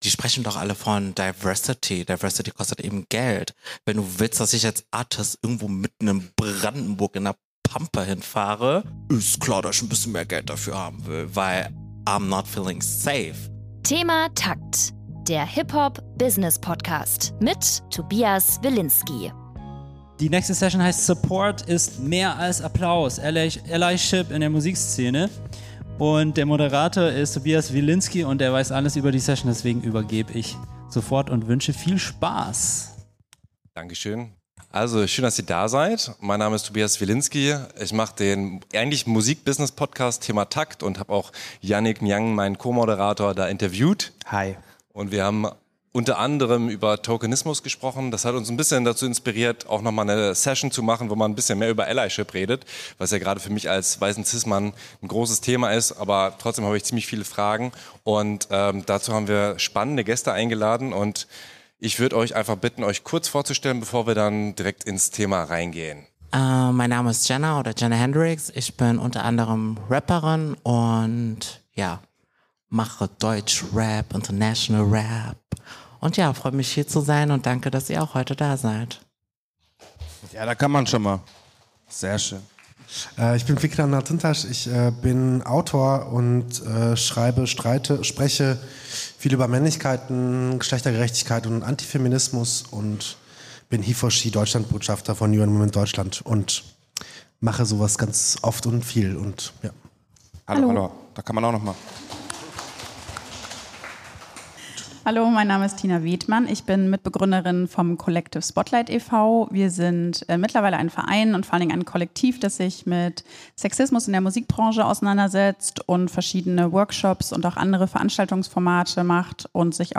Diese Folge haben wir als Live-Podcast auf der Most Wanted: Music-Konferenz 2025 aufgenommen. Entschuldigt bitte, dass der Sound teils etwas schlechter ist.